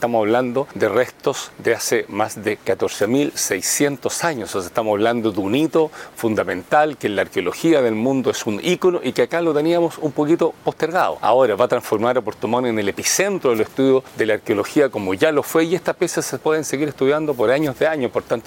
Por su parte, el gobernador de Los Lagos, Patricio Vallespín, afirmó que son cerca de 1.600 piezas las que se trasladarán.